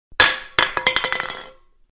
Звуки газировки
Пустая банка от газировки упала